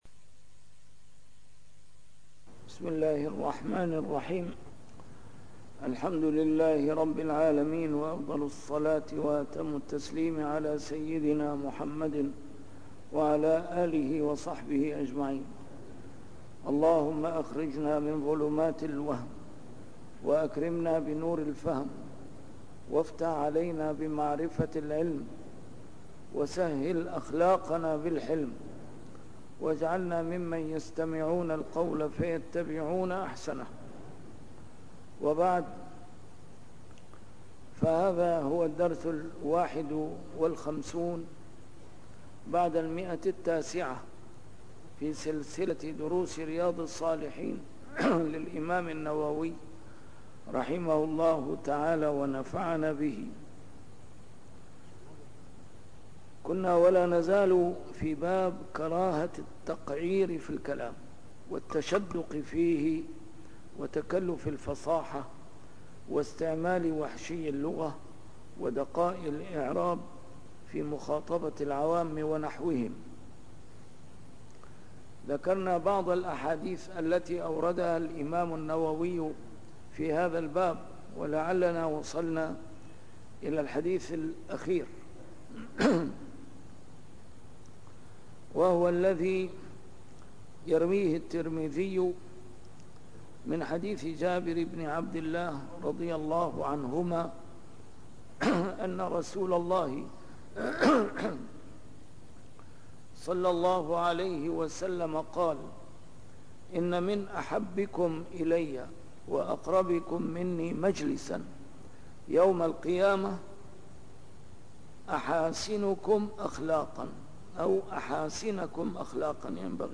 A MARTYR SCHOLAR: IMAM MUHAMMAD SAEED RAMADAN AL-BOUTI - الدروس العلمية - شرح كتاب رياض الصالحين - 951- شرح رياض الصالحين: كراهة التقعير في الكلام